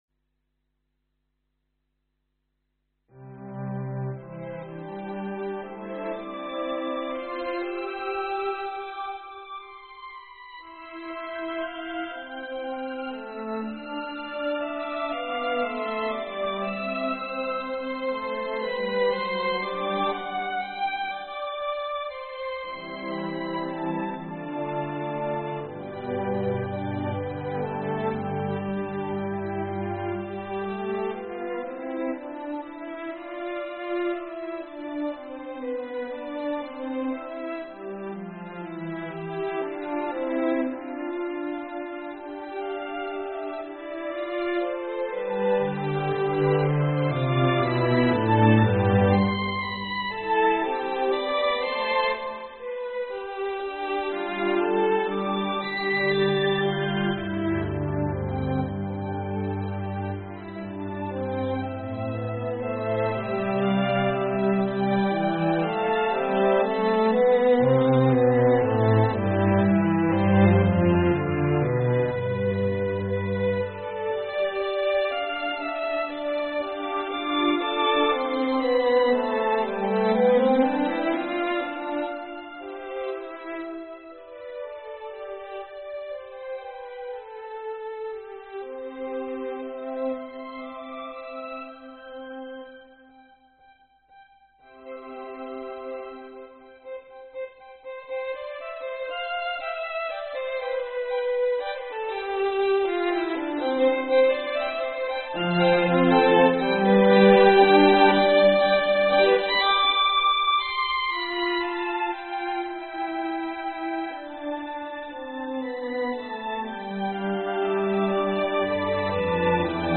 ορχηστρικής σύνθεσής